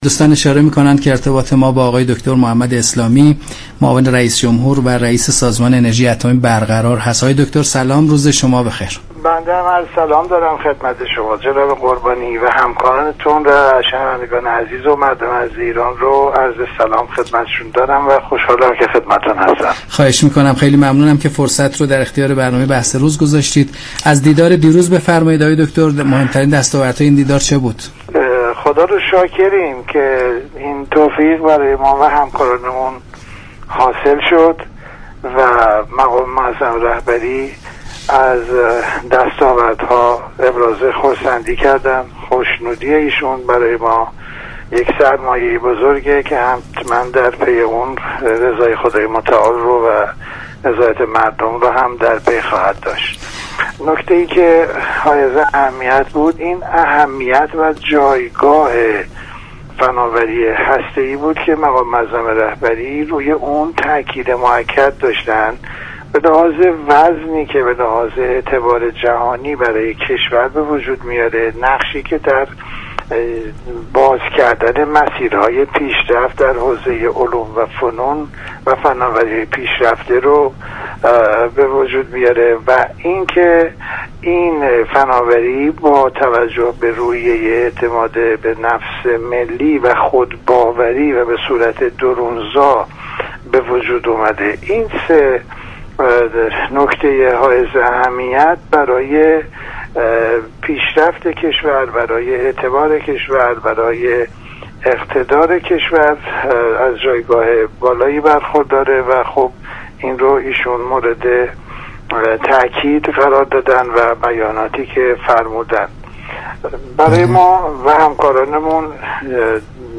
به گزارش شبكه رادیویی ایران، برنامه «ایران امروز» در راستای بازدید مقام معظم رهبری از این نمایشگاه با محمد اسلامی رییس سازمان انرژی اتمی گفت و گو كرده است.